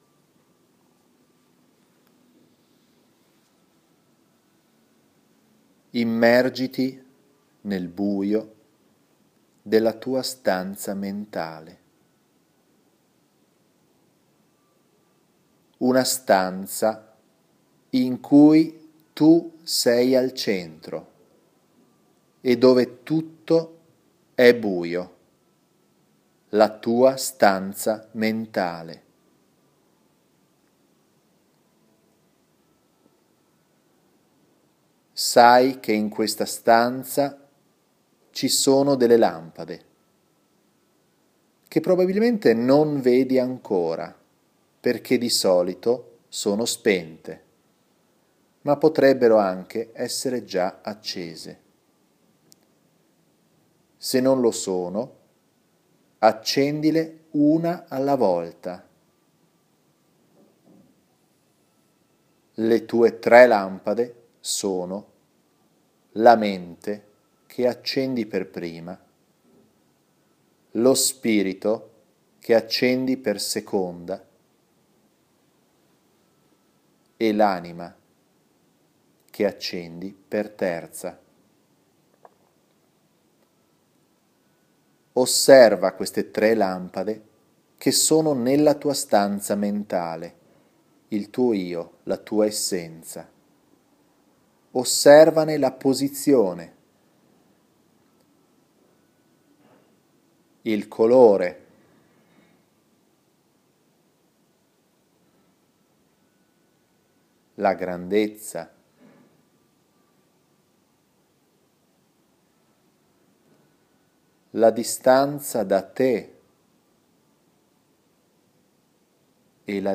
Triade Color Test Dinamico Flash - Traccia audio meditativa
Traccia audio di 17 minuti con il TCT-DFesercizio meditativo per ripristinarela Coscienza Integrata